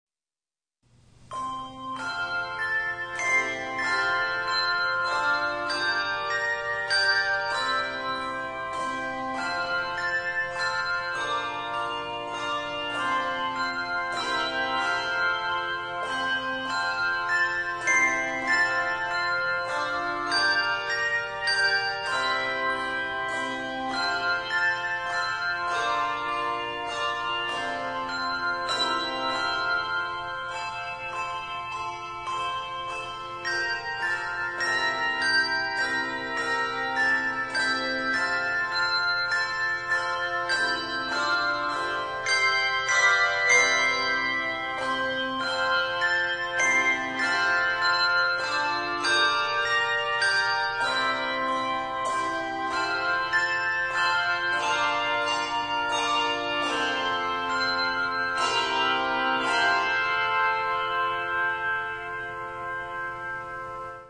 Voicing: Handchimes